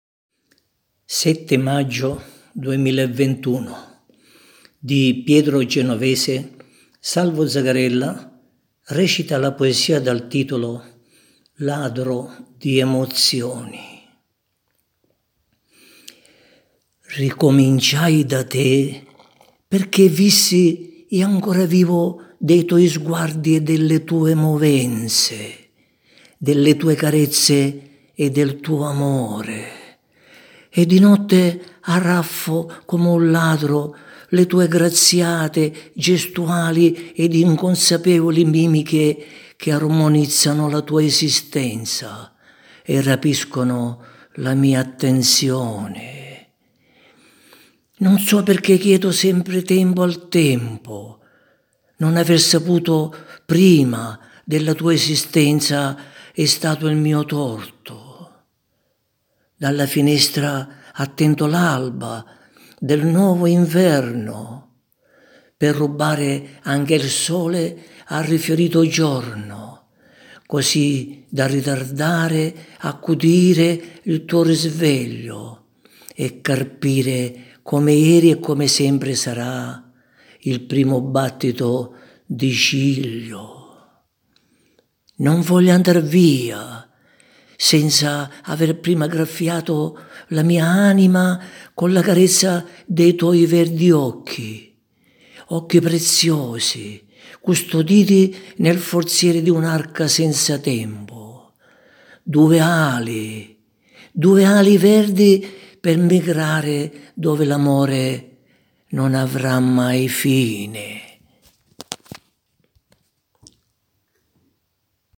interpreta la poesia ''Ladro di emozioni''